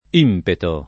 impeto [ & mpeto ] s. m.